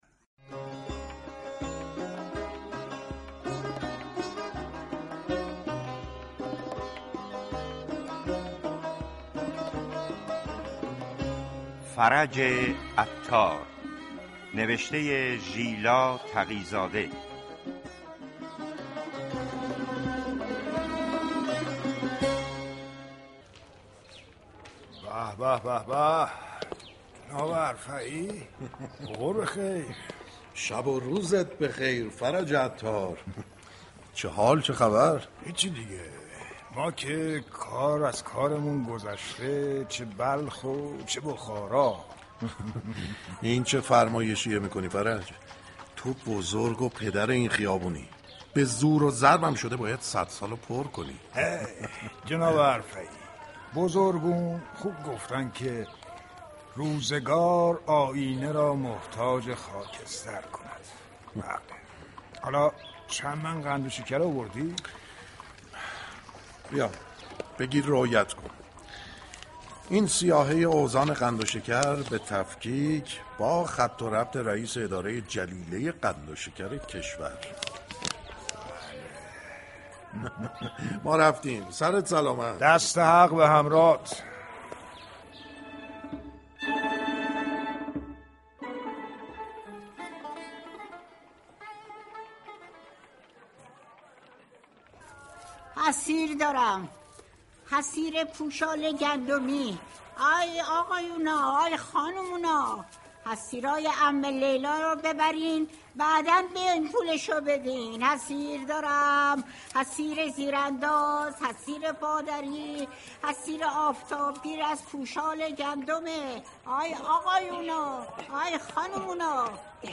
از روز سه شنبه ، نوزدهم دی ماه ، پخش سریال پلیسی جدید